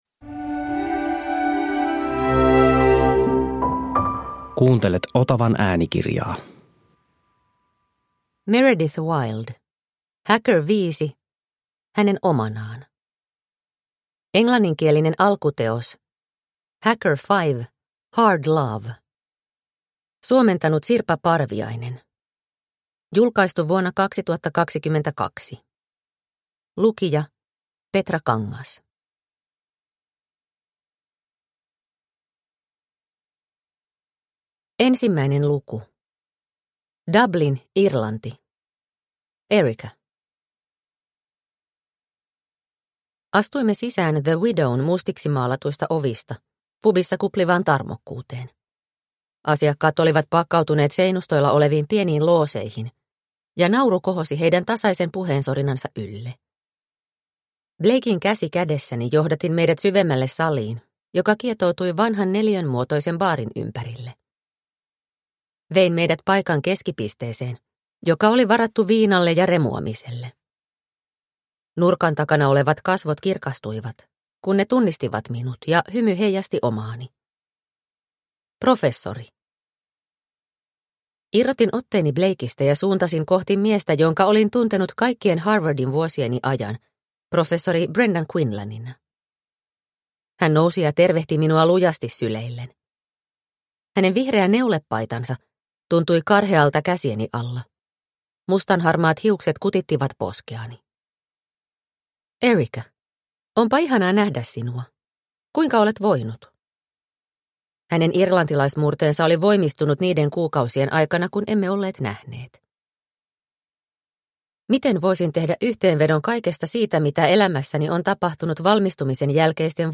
Hacker 5. Hänen omanaan – Ljudbok – Laddas ner